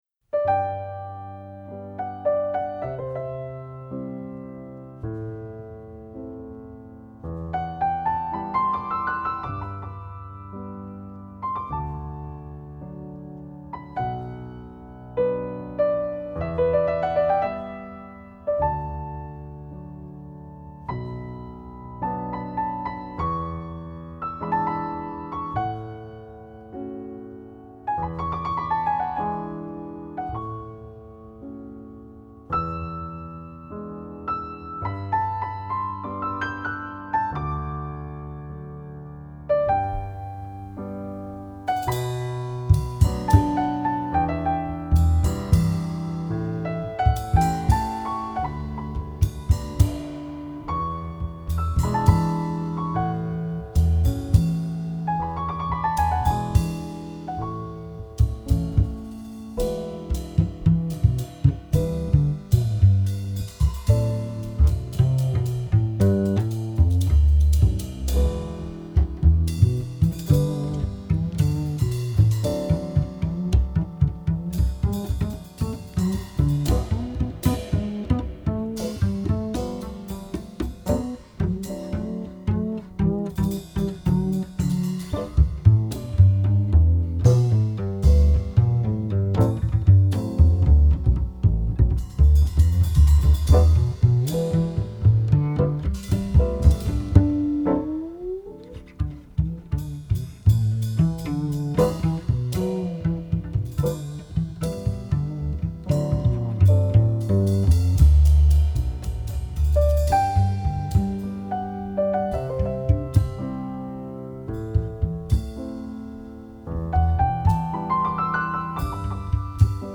音樂類別 ：爵士樂 ． 爵士三重奏
鋼琴
貝斯
鼓
路西耶三重奏团来演奏，竟然蜕变成最浪漫深情的爵士乐曲，让人陶醉得喜悦忘怀。